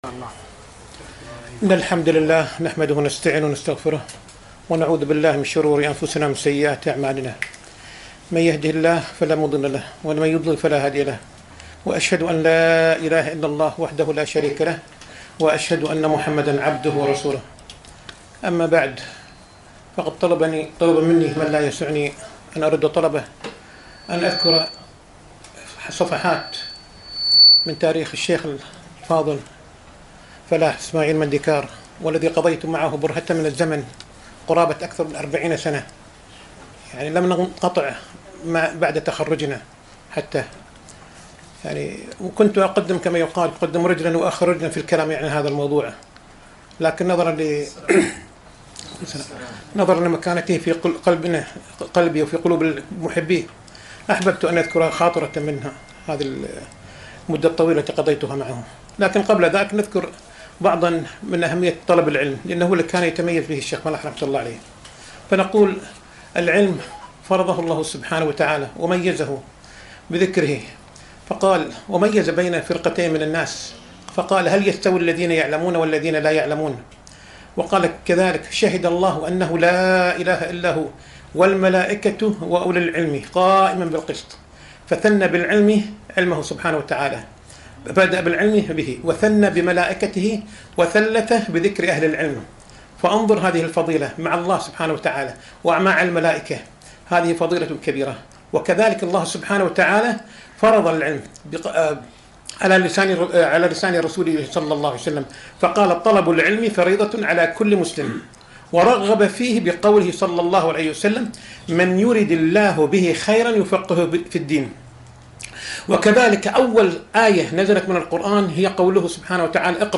محاضرة - الشيخ فلاح مندكار رحمه الله كما عرفته